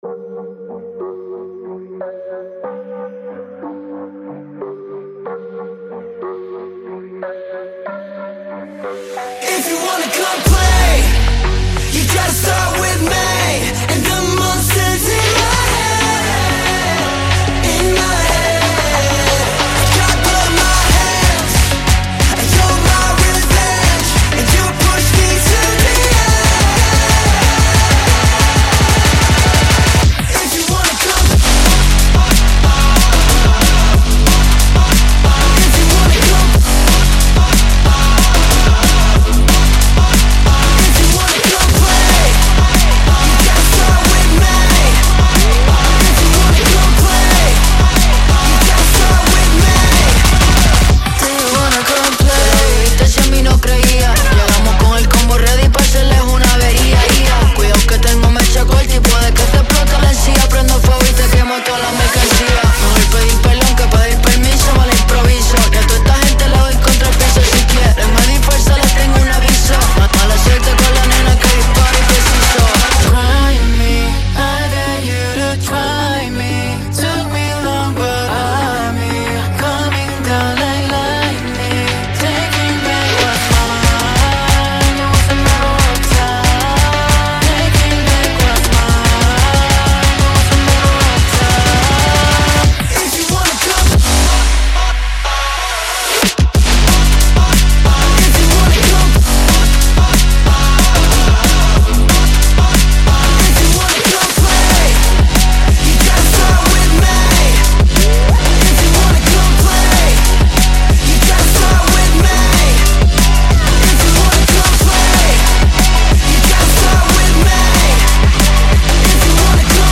Kpop song download